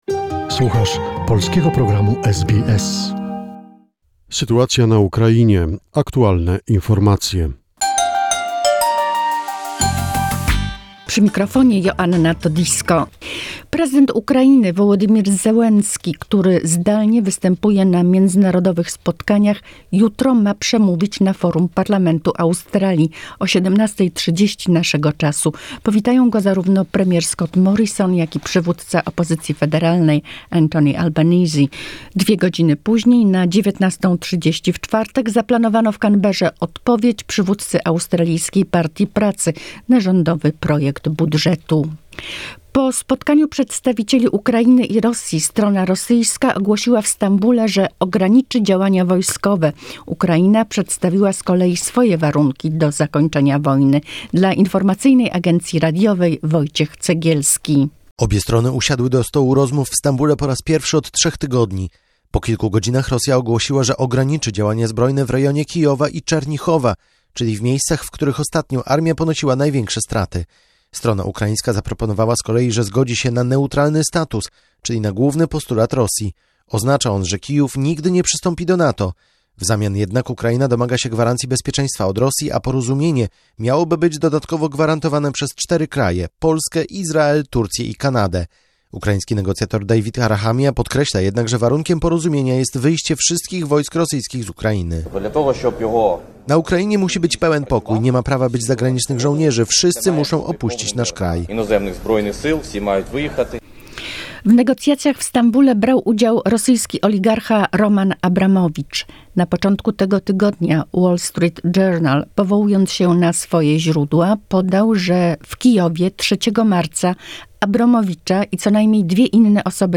The most recent information about the situation in Ukraine, a short report prepared by SBS Polish.